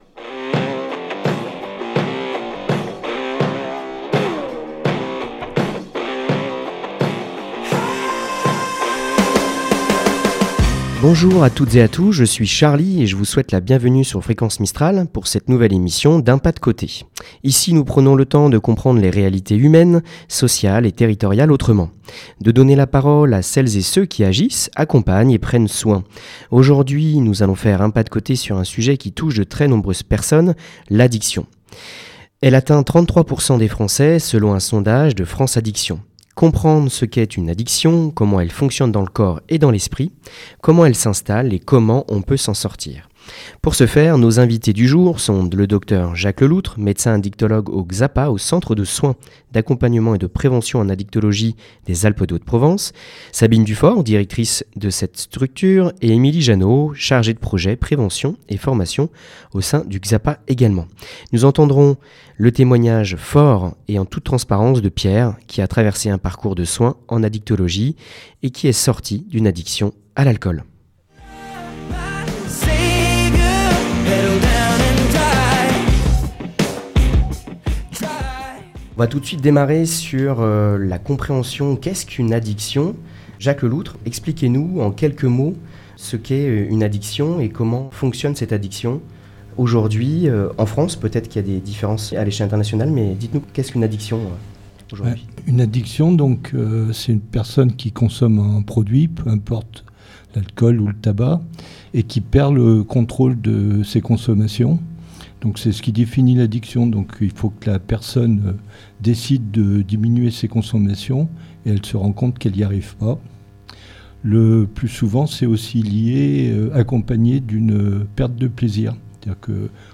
L’émission donne la parole à celles et ceux qui agissent, accompagnent et prennent soin au quotidien.